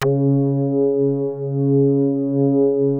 P.5 C#4.8.wav